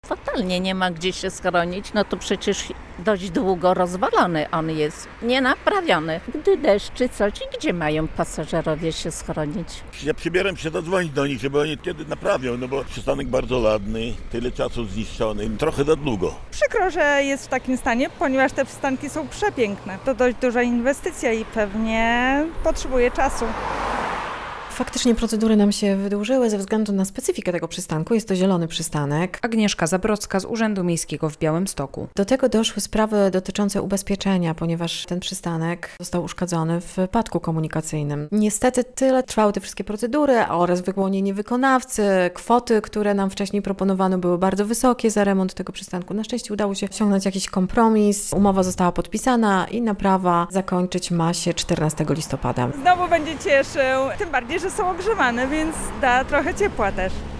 Białostoczanie przyznają, że są już zniecierpliwieni przedłużającym się remontem przystanku - relacja